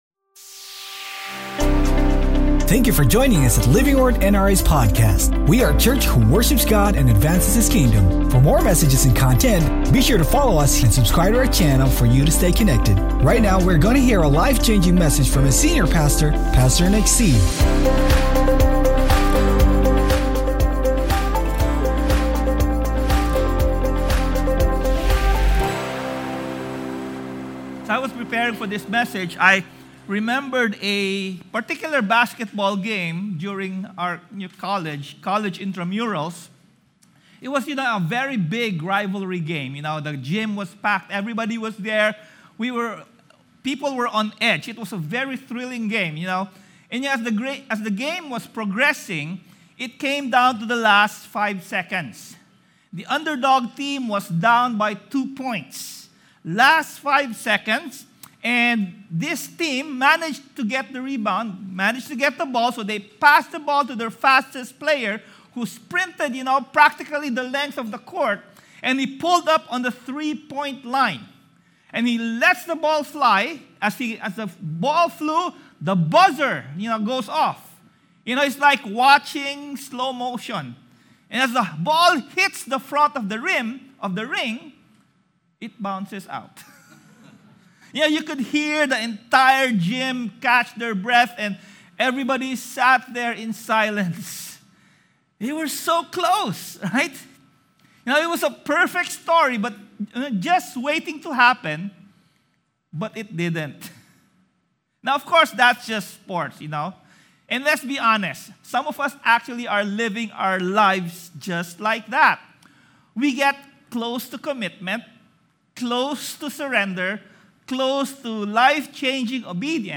Sermon Title: WHEN COURAGE ISN’T CONVENIENT